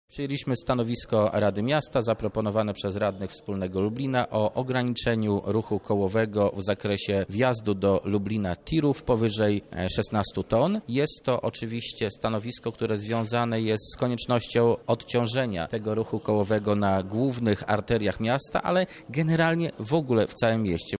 Więcej na ten temat mówi radny Wspólnego Lublina, Marcin Nowak